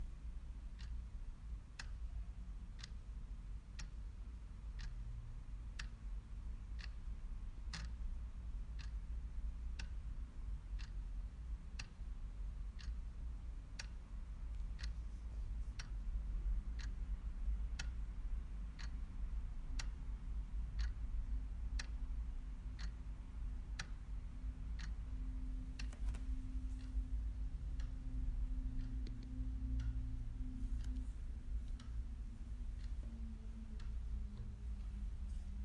模拟手表
描述：客厅中的滴答钟，以百夫长记录，用变焦H6录制，用收割机编辑。
Tag: 挂钟 OWI 时间 滴答滴答